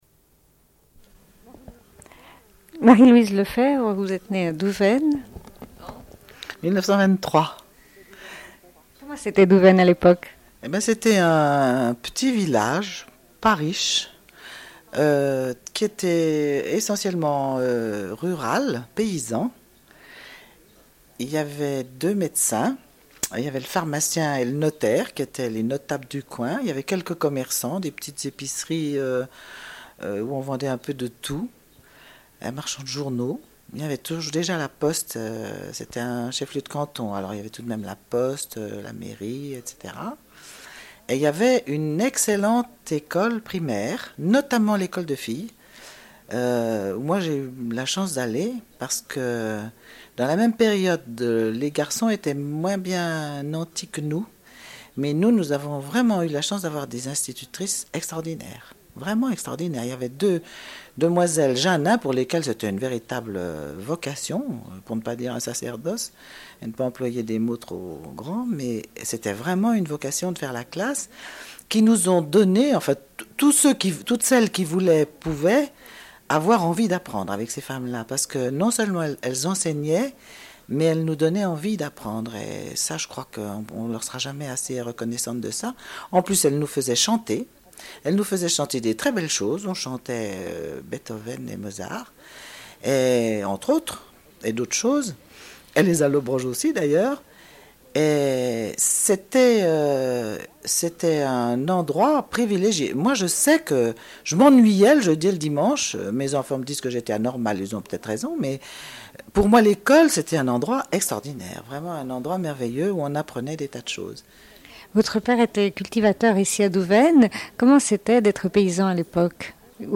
Une cassette audio, face A41:17
Rush de l'émission C-0221.